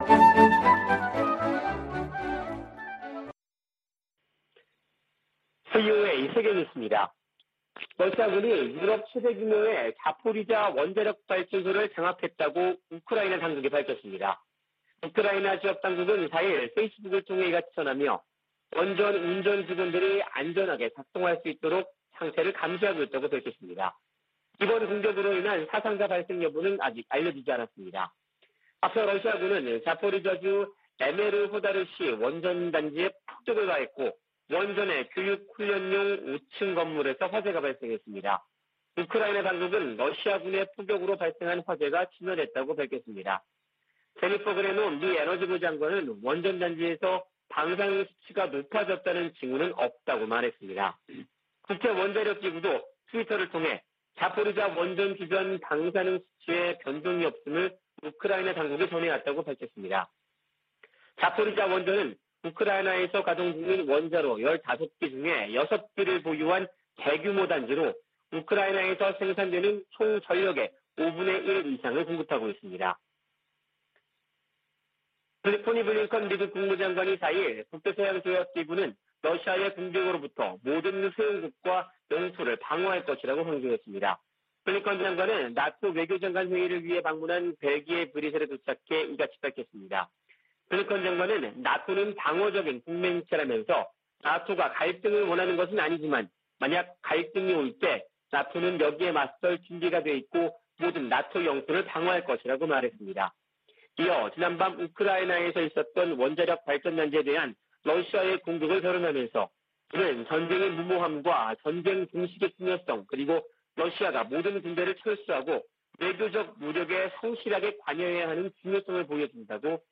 VOA 한국어 아침 뉴스 프로그램 '워싱턴 뉴스 광장' 2022년 3월 5일 방송입니다. 미 국무부는 러시아가 한국의 제재 동참에 유감을 표명한 데 대해, 국제사회가 무의미한 전쟁을 방어하는데 단결하고 있다고 강조했습니다. 북한이 우크라이나 사태에 러시아를 적극 두둔한 것은 핵 보유국 지위 확보를 노린 것이라는 분석이 나오고 있습니다. 미 상원의원들이 북한 등을 암호화폐 악용 국가로 지목하고 대책 마련을 촉구했습니다.